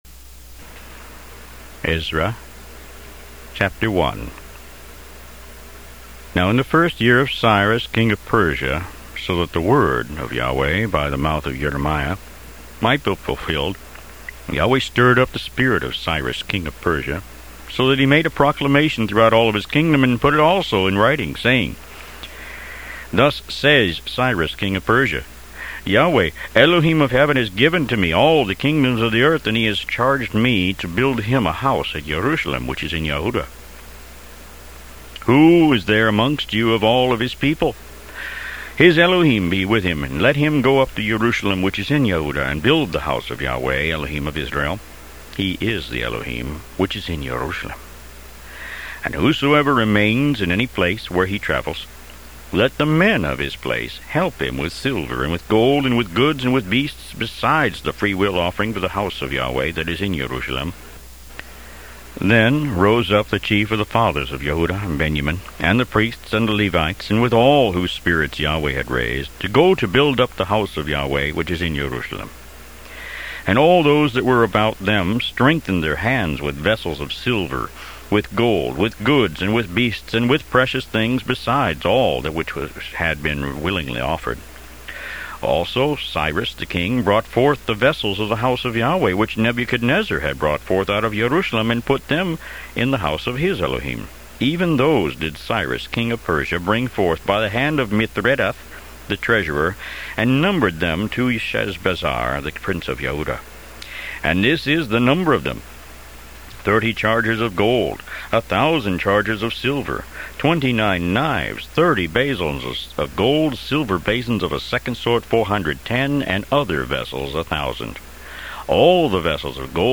Root > BOOKS > Biblical (Books) > Audio Bibles > Tanakh - Jewish Bible - Audiobook > 15 Ezra